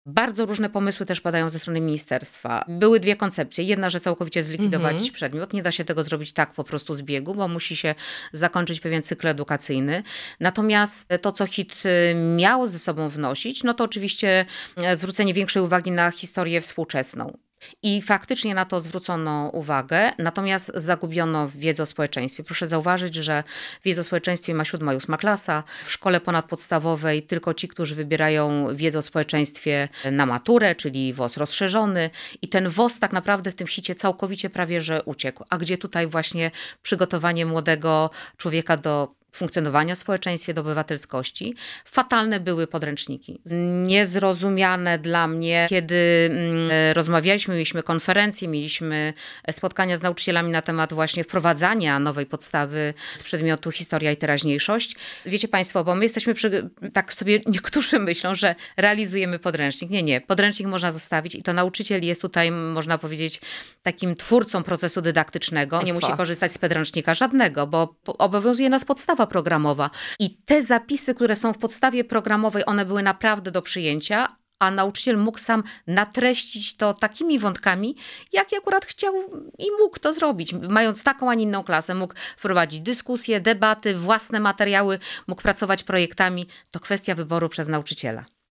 -Fatalne były podręczniki do tego przedmiotu, mówi Ewa Skrzywanek – pełniąca obowiązki dolnośląskiego kuratora oświaty.